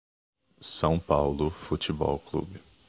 São Paulo Futebol Clube (Brazilian Portuguese: [sɐ̃w ˈpawlu futʃiˈbɔw ˈklubi]
Sao-paulo-futebol-clube-pronunciation-ptbr.ogg.mp3